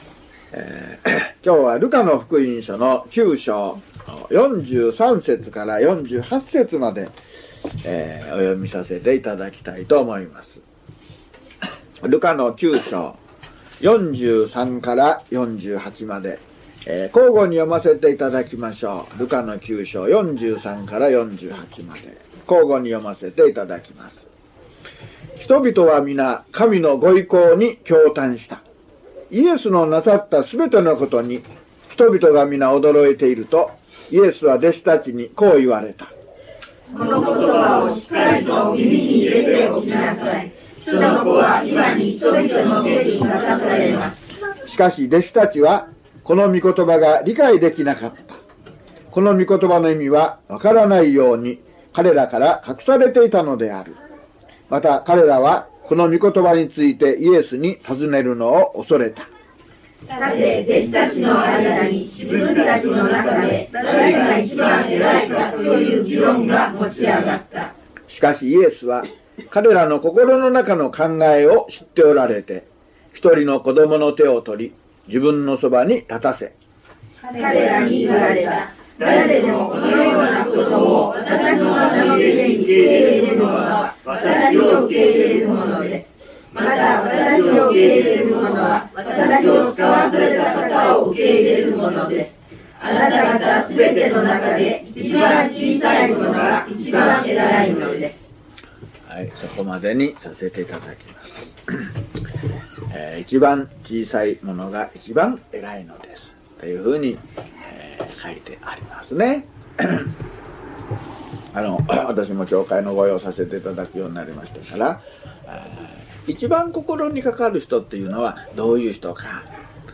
luke068mono.mp3